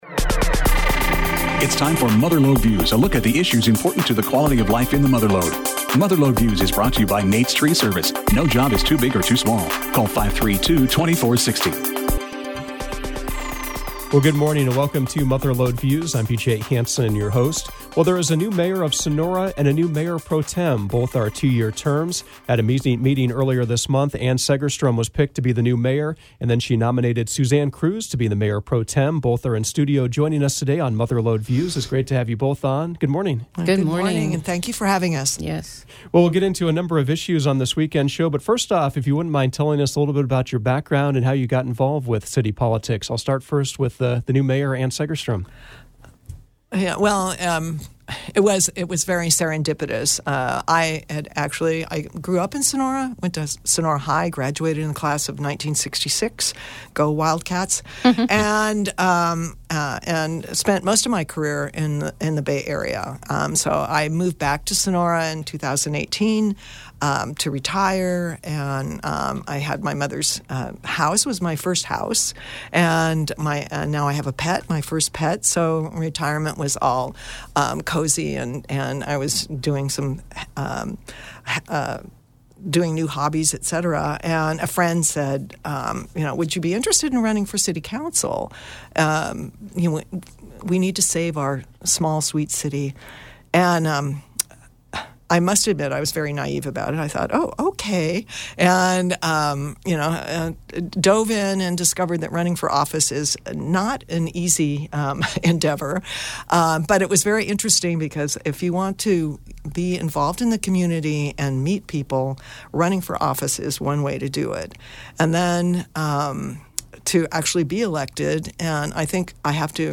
Mother Lode Views featured the new Mayor of Sonora, Ann Segerstrom, and the Mayor Pro Tem, Suzanne Cruz.